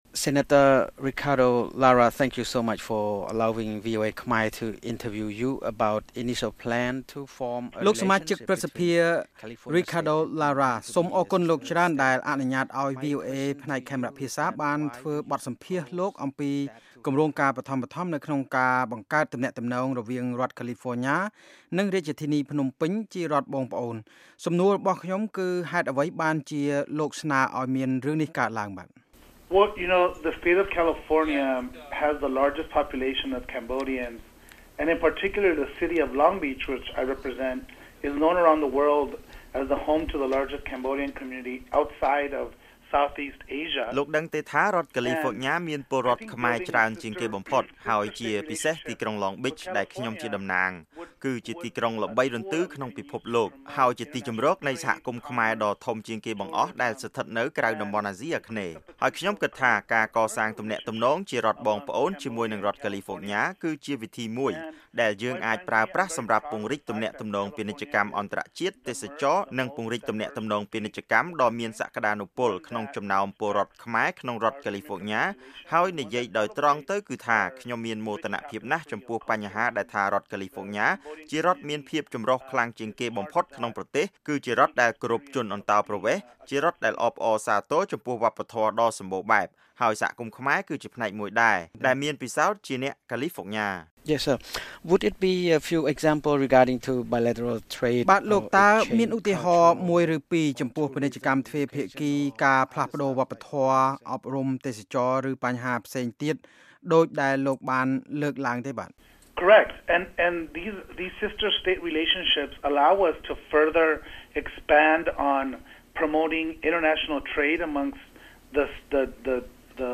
បទសម្ភាសន៍ VOA៖ ព្រឹទ្ធសភារដ្ឋកាលីហ្វ័រញ៉ាម្នាក់គាំទ្រសម្ព័ន្ធភាពជារដ្ឋបងប្អូនជាមួយកម្ពុជា